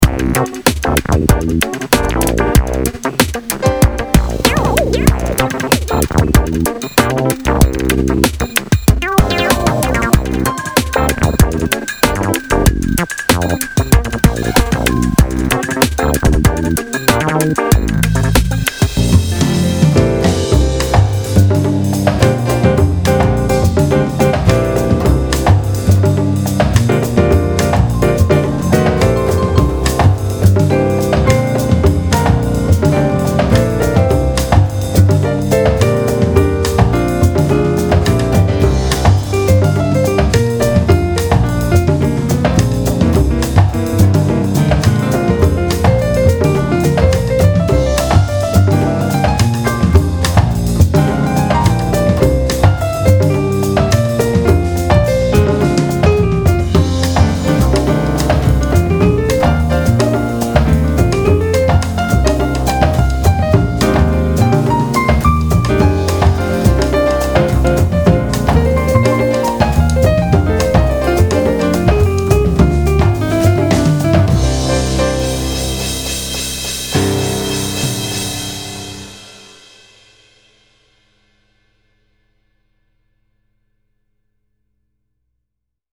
6-jazzy.mp3